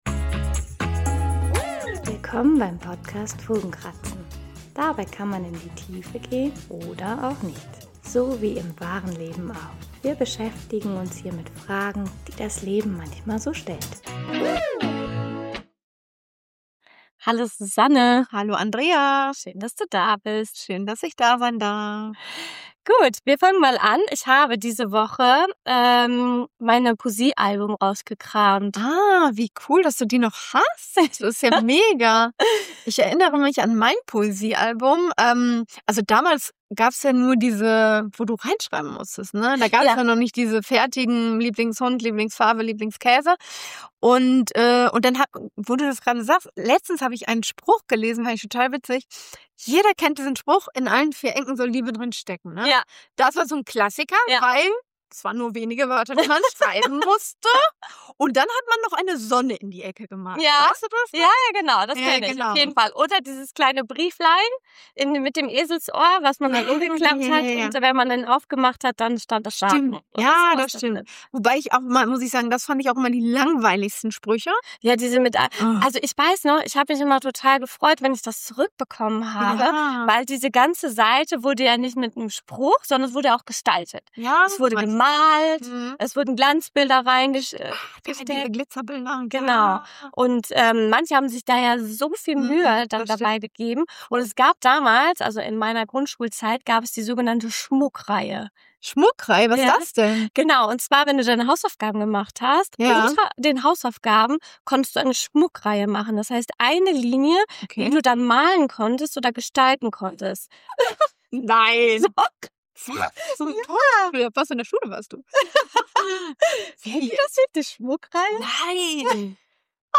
Über diese Erinnerungen kommen wir ins Gespräch, teilen persönliche Kindheitserfahrungen und entdecken, wie viel Vergangenheit noch in uns nachklingt.